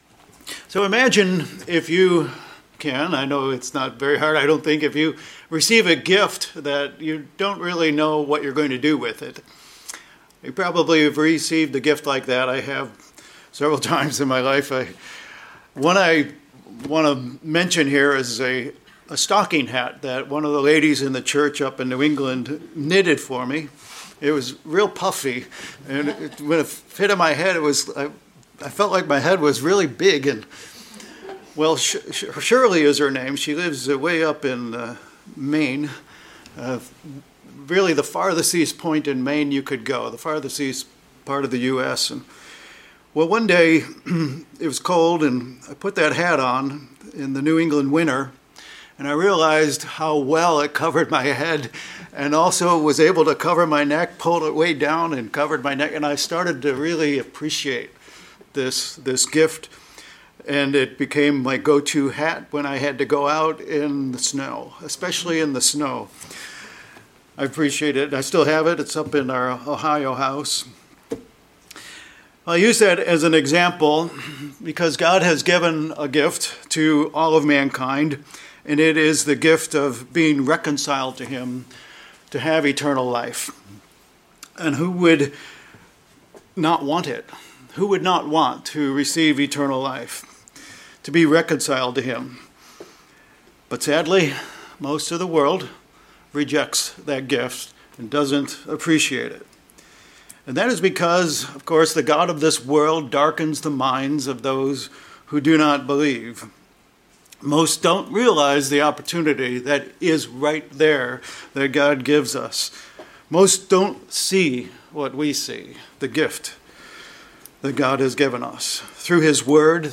Sermons
Given in Vero Beach, FL